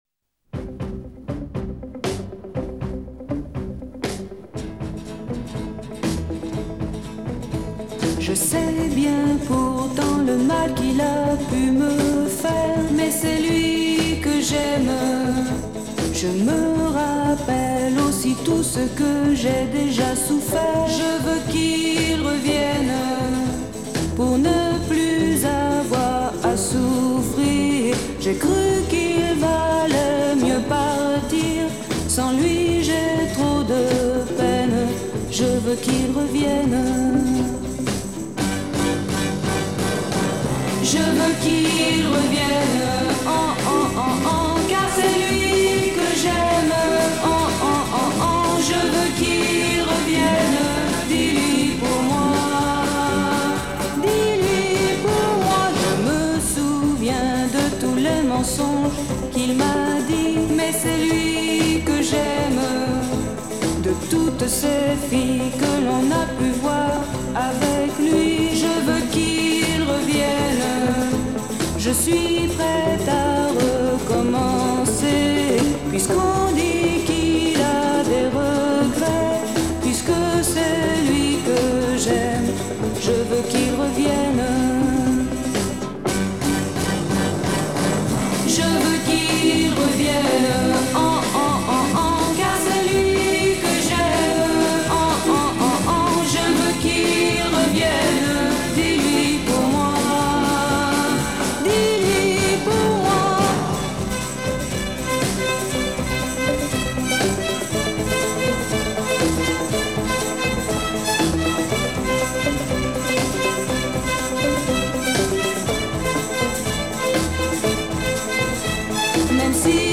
The dreamy voice other dreamy voices aspire to.